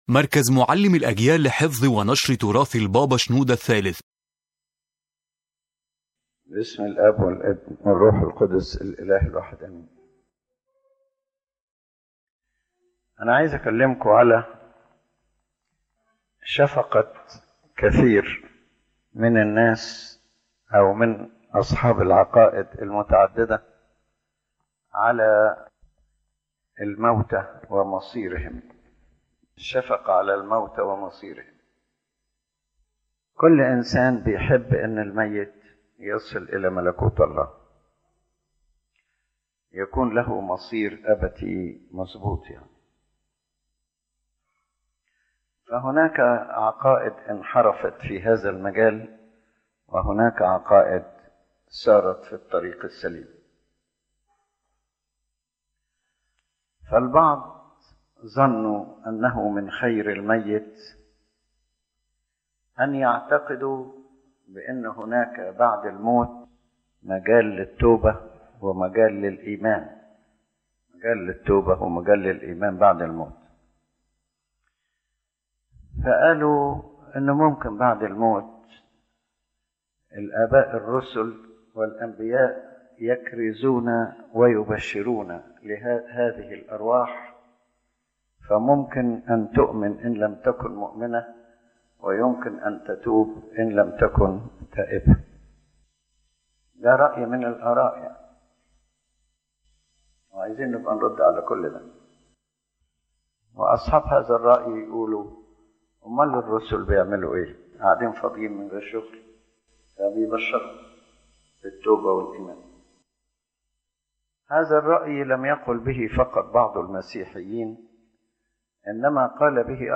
This lecture addresses the concept of compassion toward the dead and explains how some doctrines, motivated by love and compassion, became distorted by inventing unbiblical ideas about repentance or faith after death, salvation through reincarnation, purgatory, or the apostles preaching in the other world.